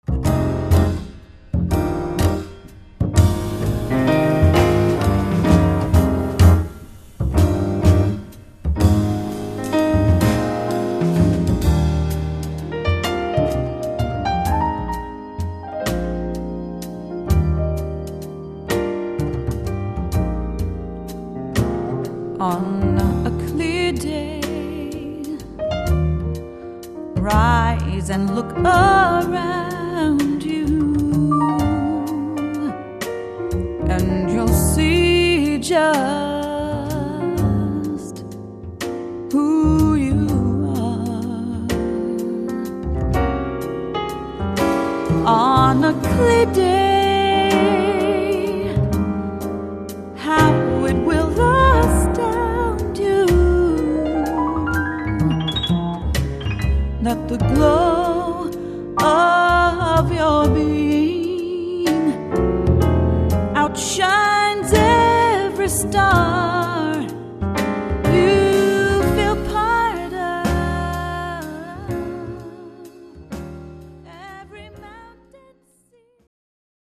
vocals
piano, organ
bass
drums
percussion